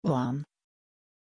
Aussprache von Shuan
pronunciation-shuan-sv.mp3